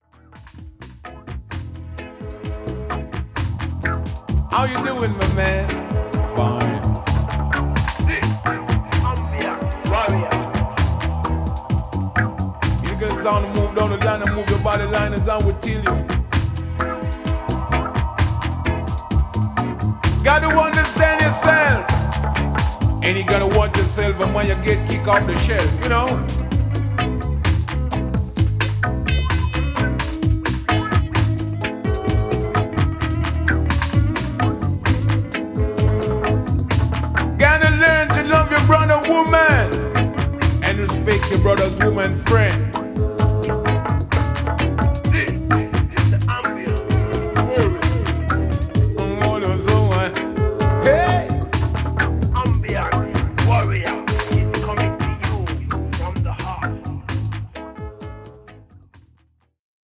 ホーム ｜ JAMAICAN MUSIC > DUB
ダブ・プロジェクト